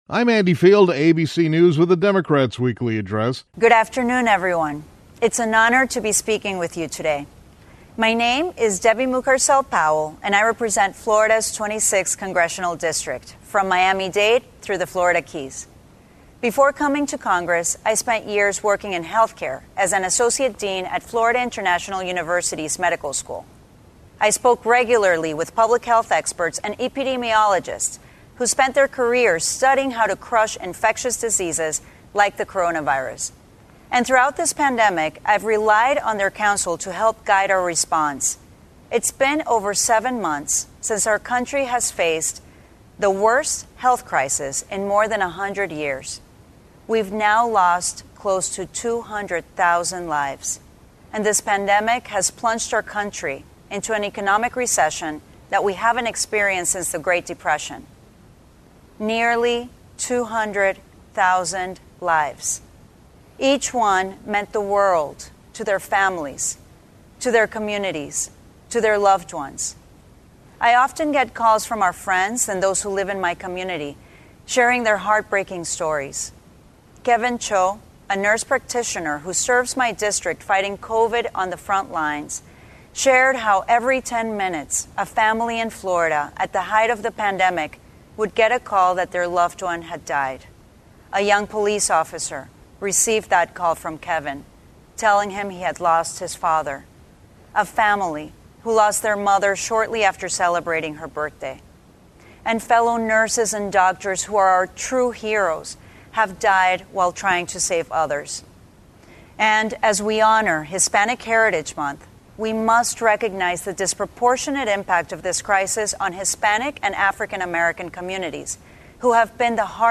Congresswoman Debbie Mucarsel-Powell of Florida delivered the Weekly Democratic Address.